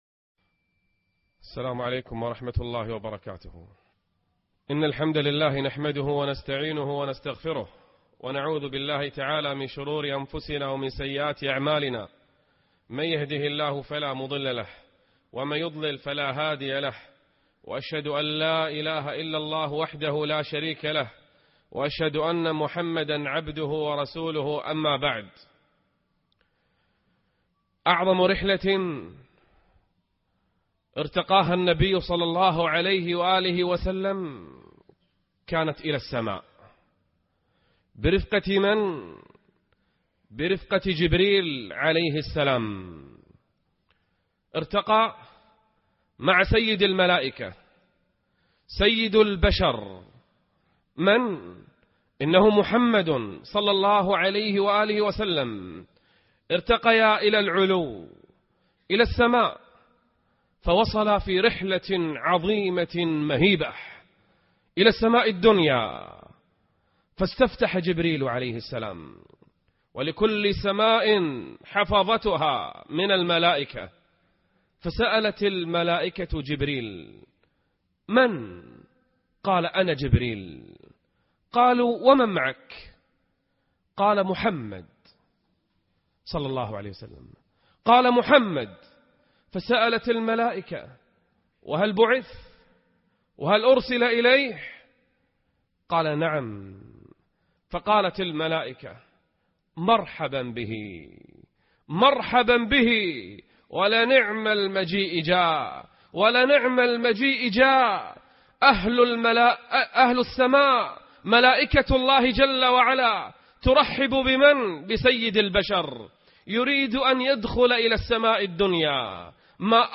نعم المجيء( 30/5/2014)خطب الجمعة - فضيلة الشيخ نبيل العوضي